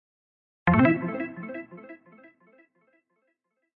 clear_success1.mp3